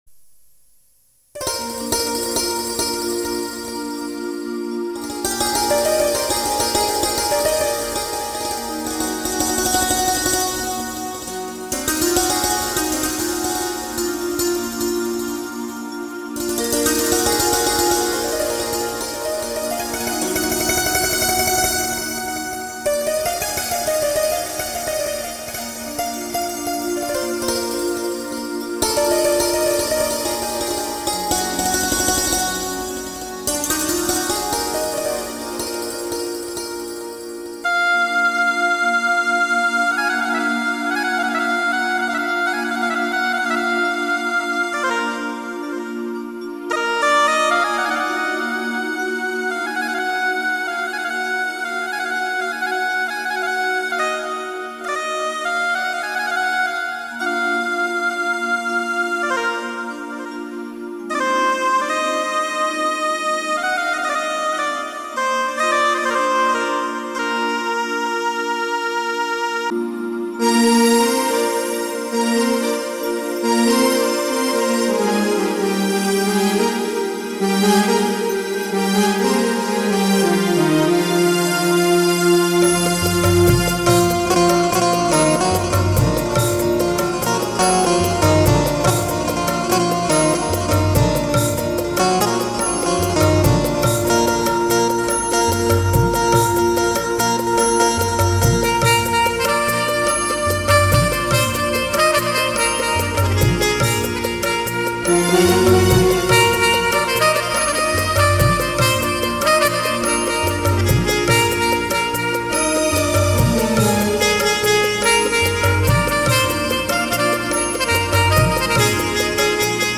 Instrumental Music And Rhythm Track Songs Download
Another instrumental cover of vintage Odia song